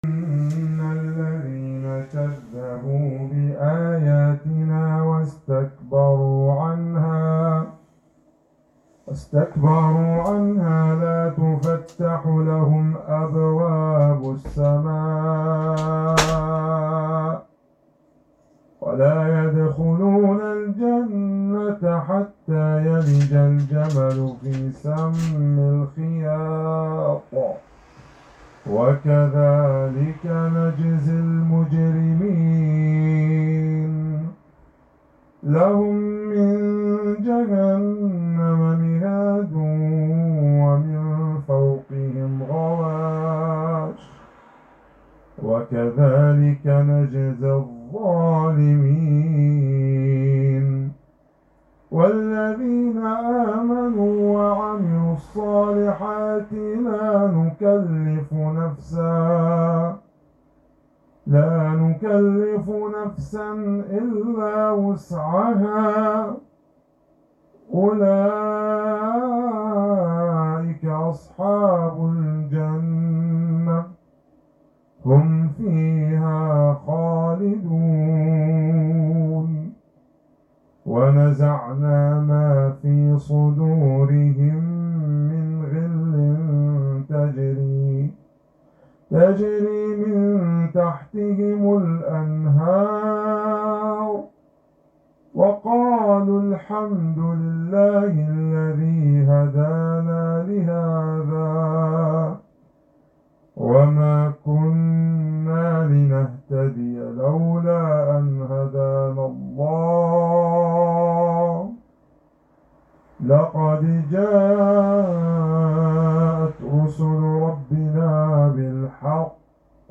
تلاوات قرآنية